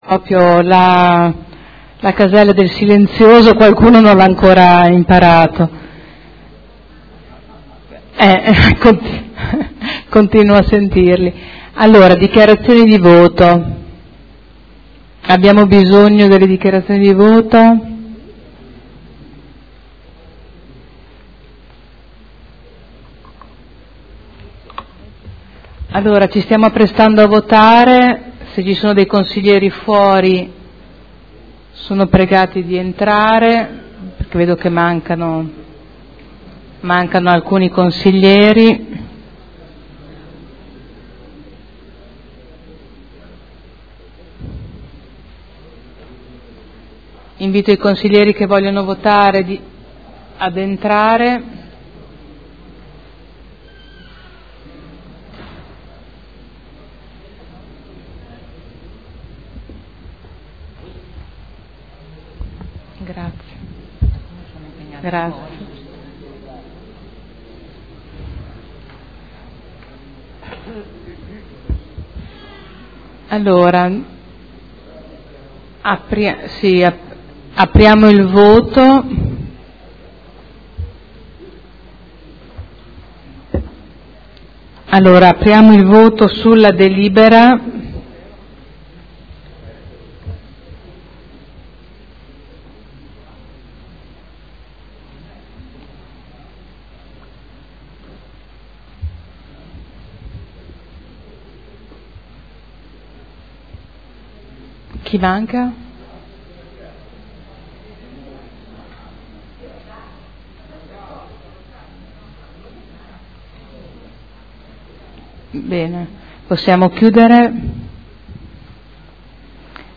Presidente — Sito Audio Consiglio Comunale
Seduta del 02/07/2012.